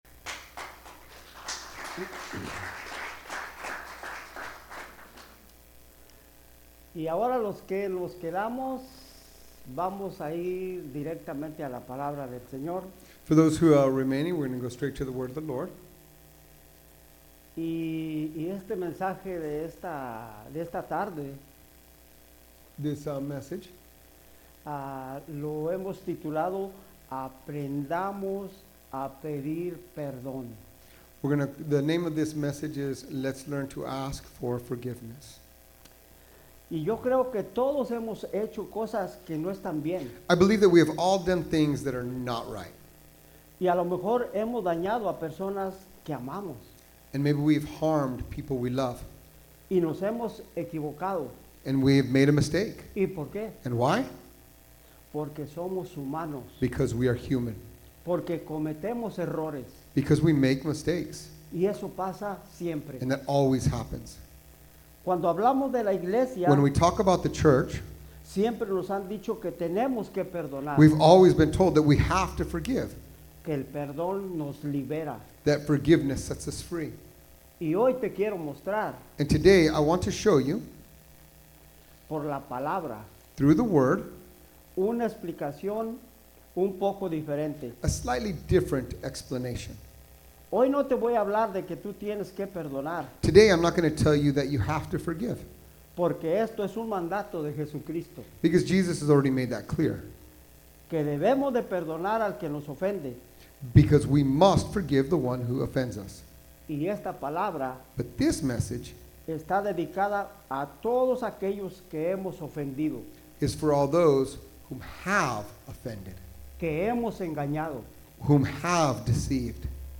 Sermons | Mercy Springs Church of the Nazarene
We were so blessed to see everyone who joined us for our bilingual service this Sunday.